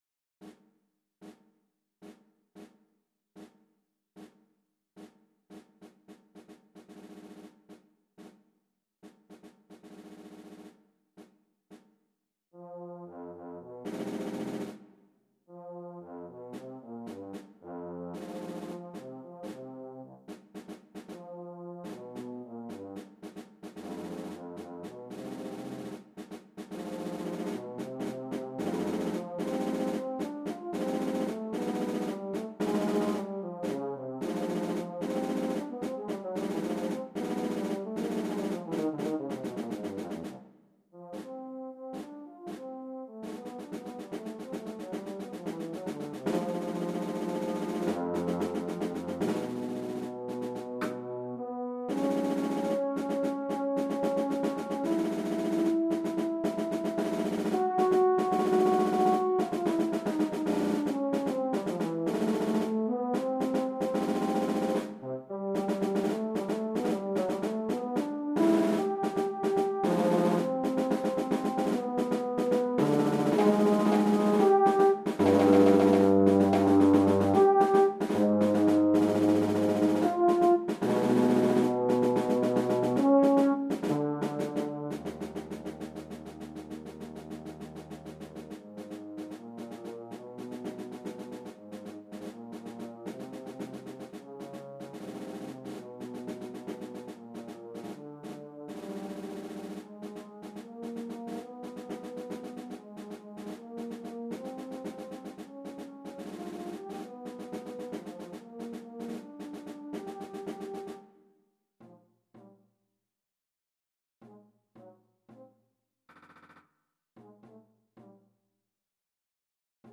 Saxhorn ou Euphonium et Caisse Claire